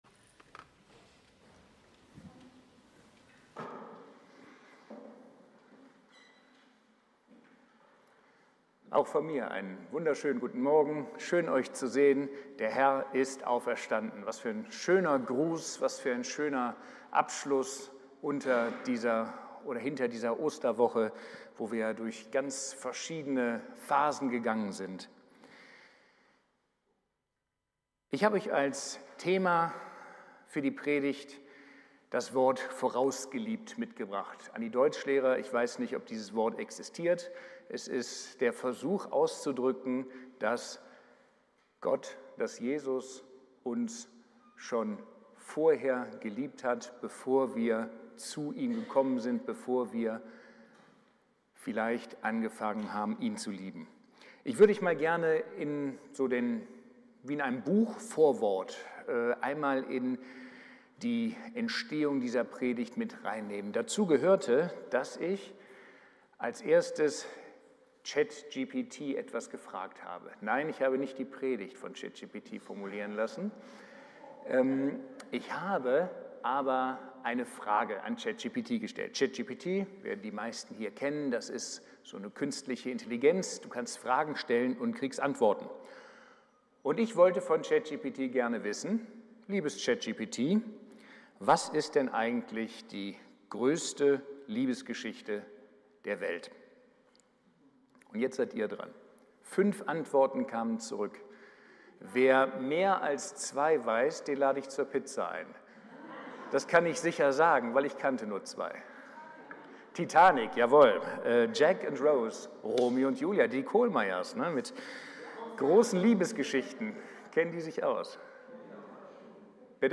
Predigt-Ostergottesdienst-05.04-online-audio-converter.com_.mp3